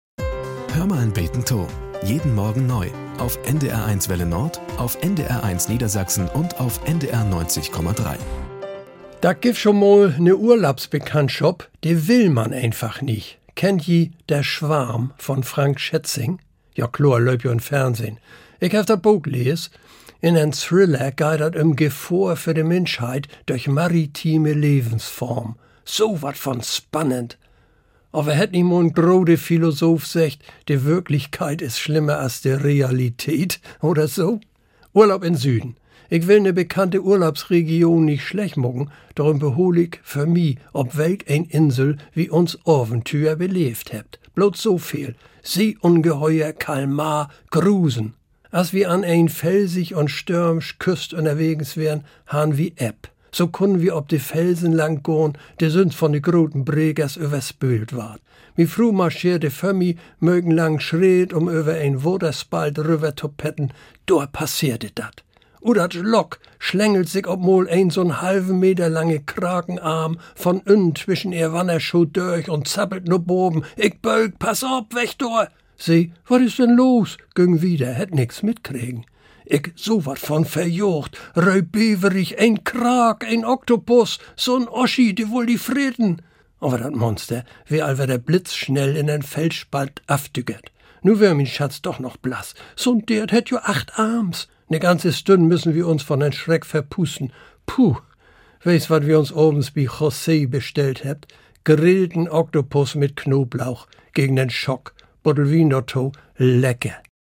Nachrichten - 09.08.2023